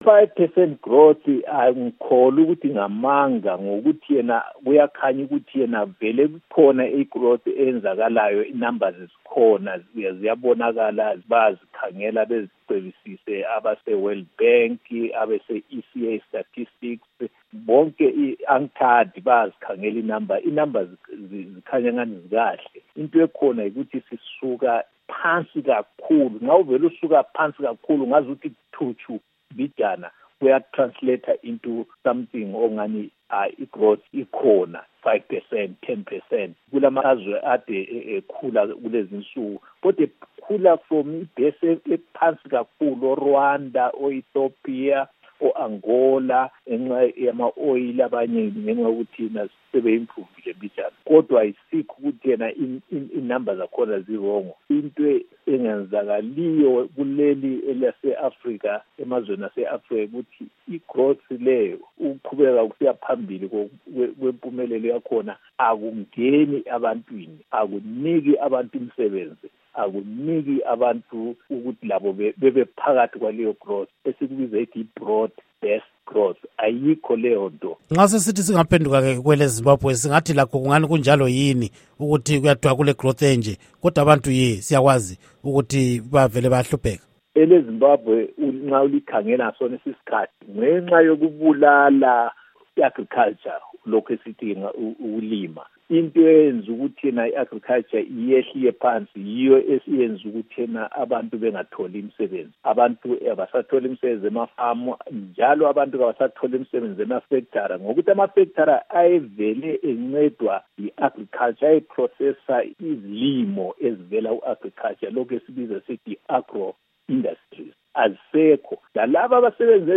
Embed share Ingxoxo Esiyenze LoMnu.